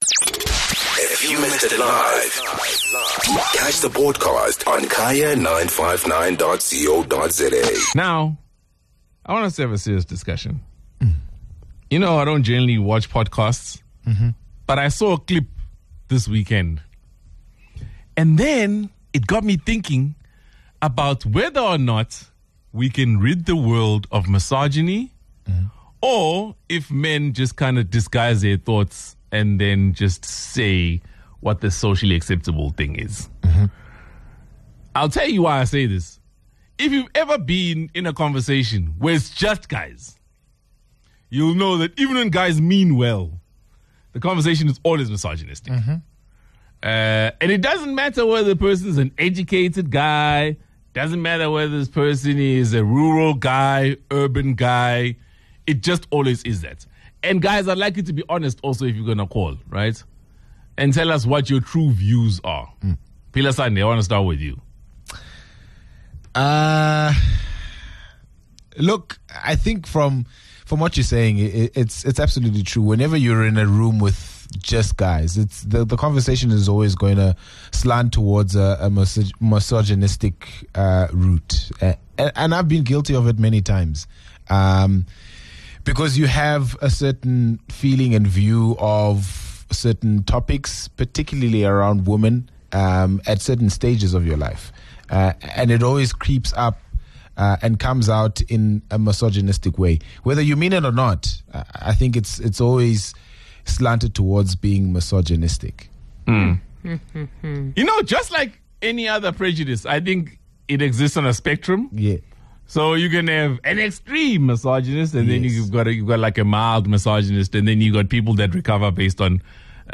The widespread of podcasts has amplified the spotlight and observation on men and their misogyny. The team and listeners held an honest and spirited discussion about this prejudice.